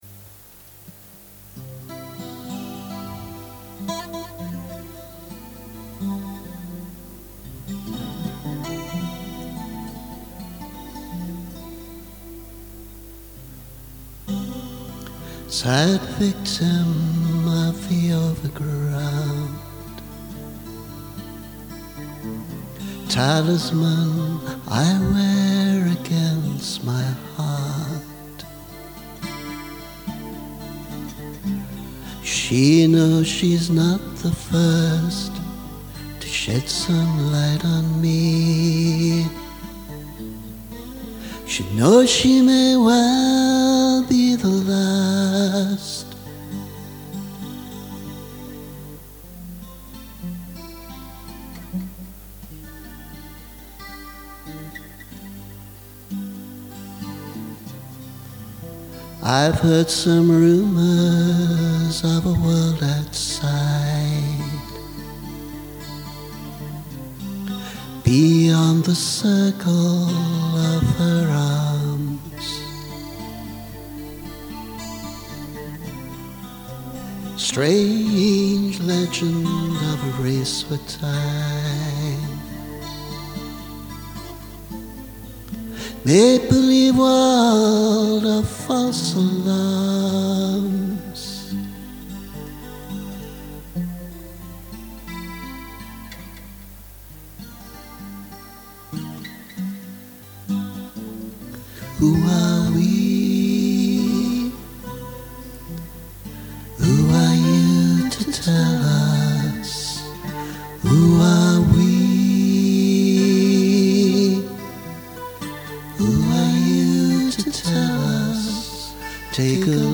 A more tentative arrangement: